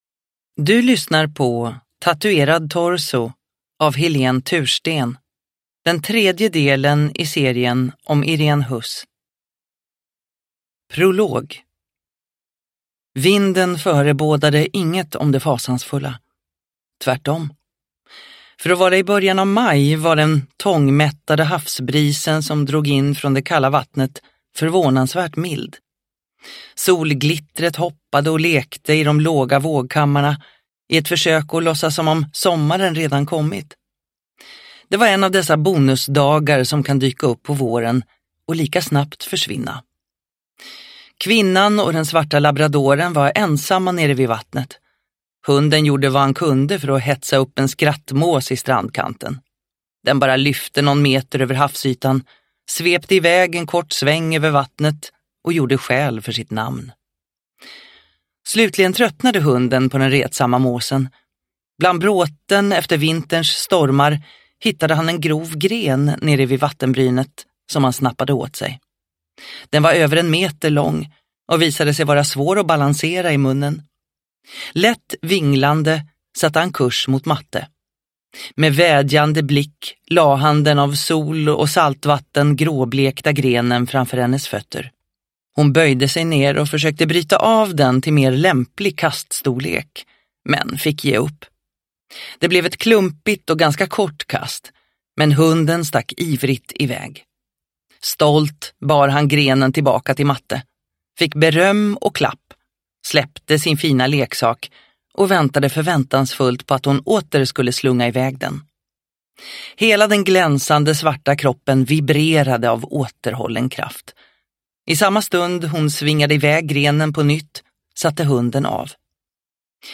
Tatuerad torso – Ljudbok – Laddas ner